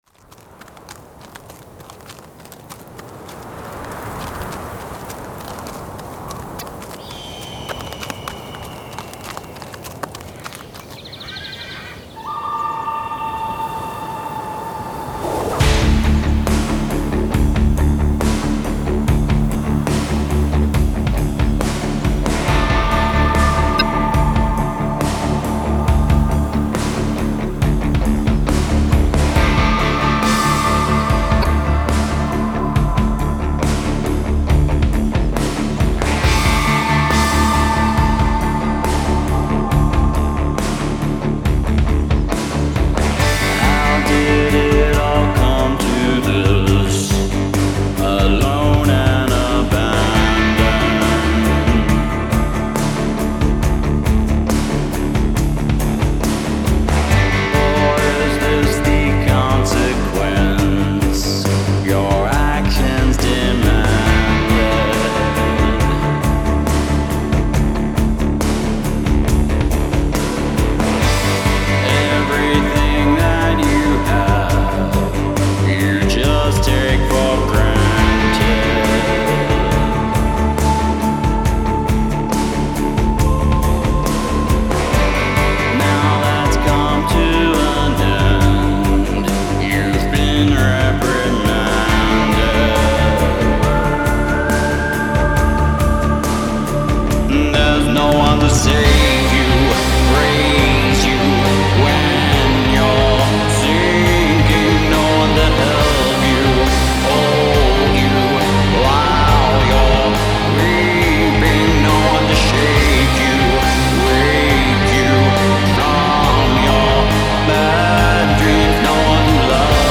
Industrial